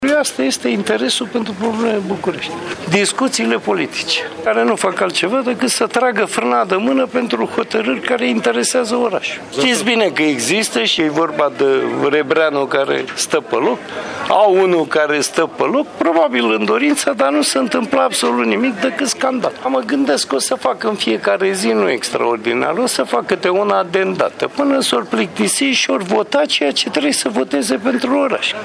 Ședință furtunoasă a Consiliului General al Municipiului București
Oprescu-sedinta.mp3